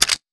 weapon_foley_pickup_04.wav